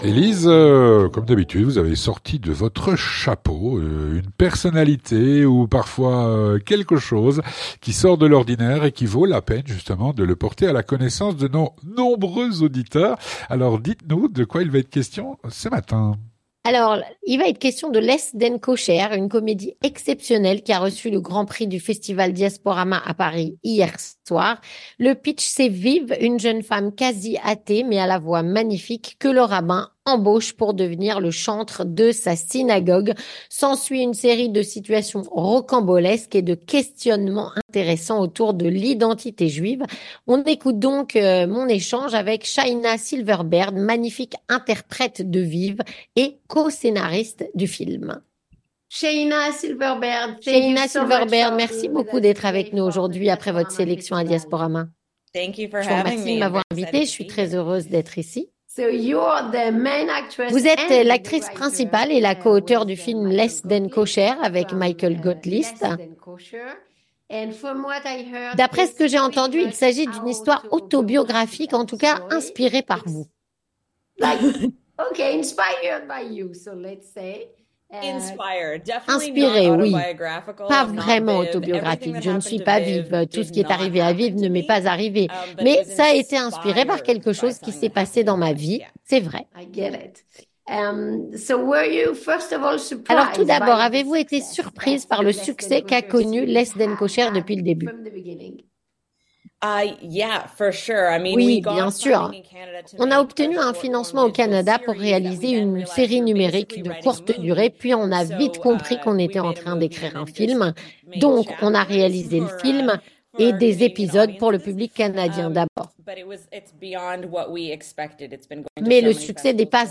Rencontre - “Less than kosher” a reçu le Grand Prix du festival Diasporama, à Paris, hier soir.